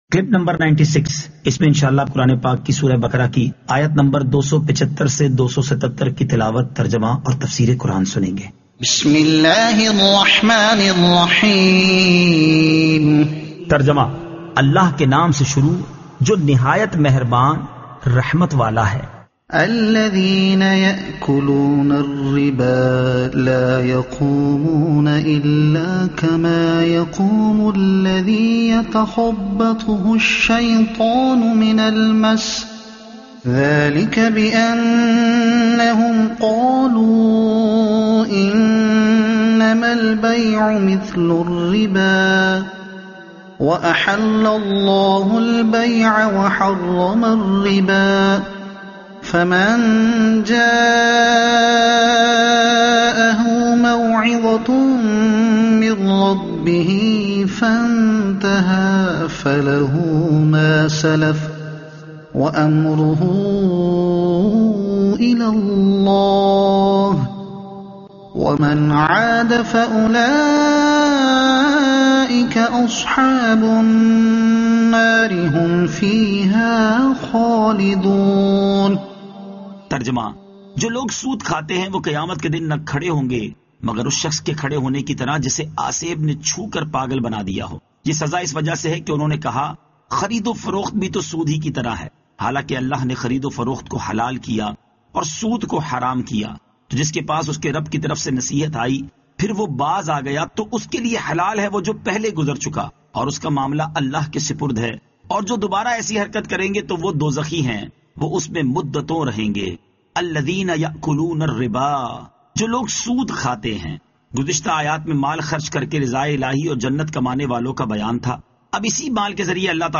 Surah Al-Baqara Ayat 275 To 277 Tilawat , Tarjuma , Tafseer